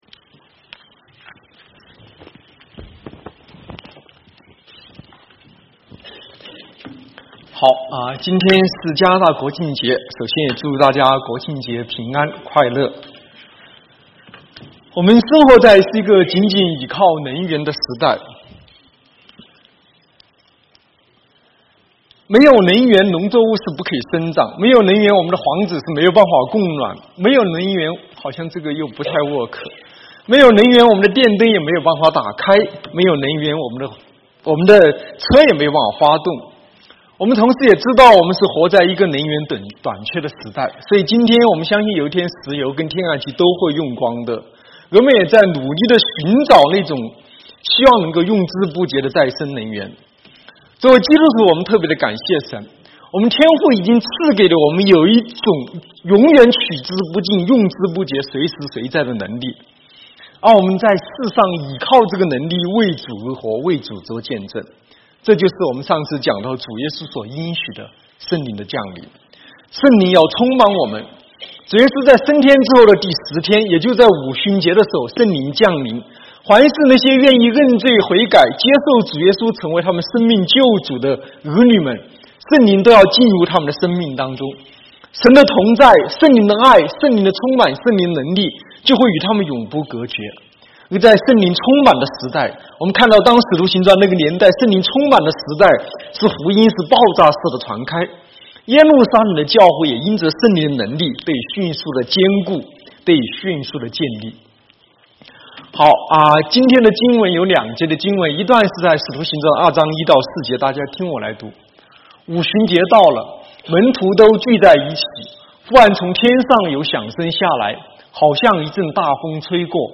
Sermons | South Gate Alliance Church | Mandarin (國語)